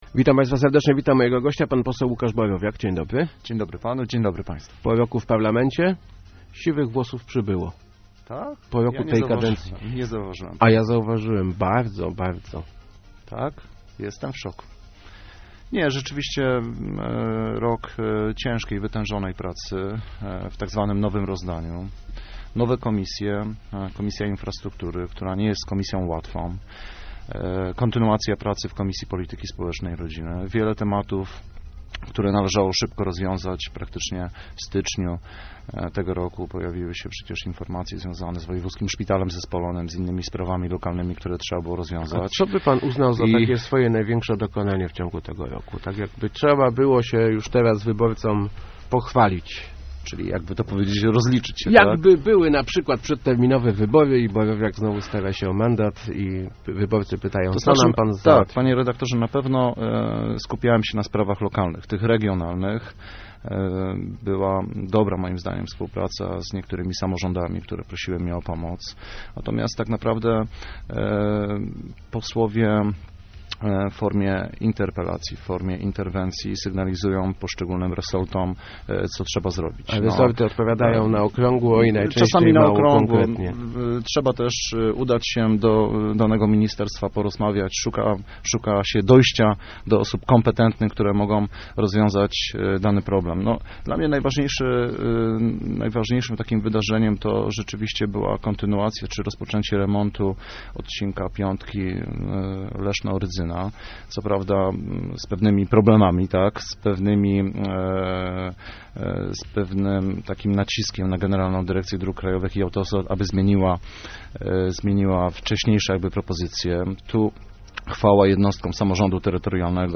W polityce nie można być indywidualistą, ale czasem trzeba mieć swoje zdanie - mówił w Rozmowach Elki poseł PO Łukasz Borowiak. Parlamentarzysta przyznał, że nie poparł wniosku o Trybunał Stanu dla Jarosława Kaczyńskiego i Zbigniewa Ziobry.